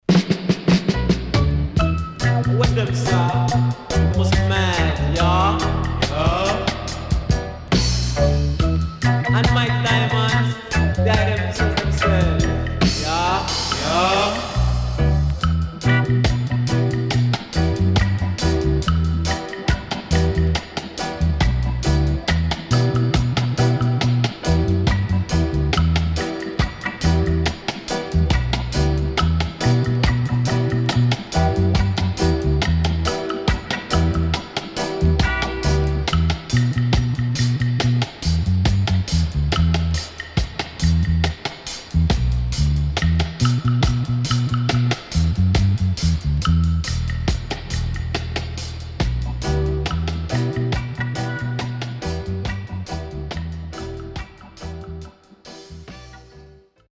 SIDE A:少しチリノイズ、プチノイズ入りますが良好です。